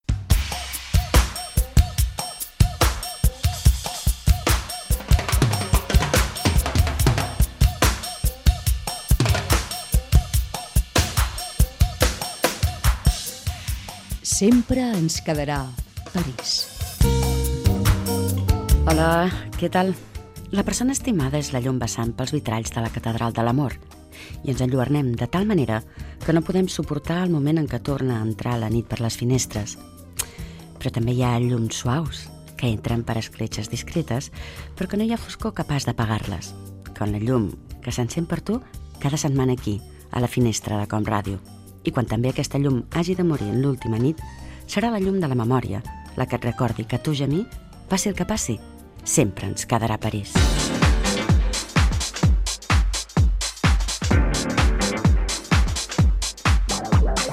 Començament del programa nocturn amb música i confidències.
Entreteniment
FM